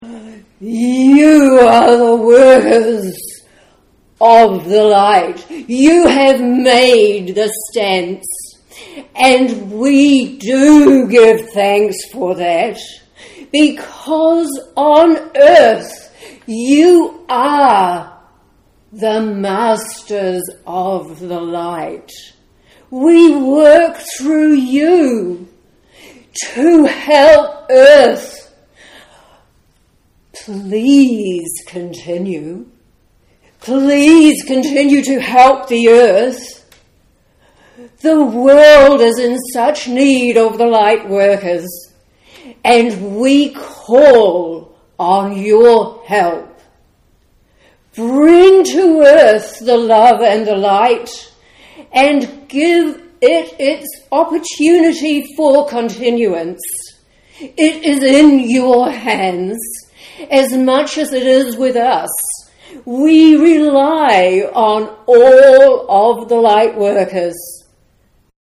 Bear in mind that it is a live recording and please excuse the deficiencies in sound quality.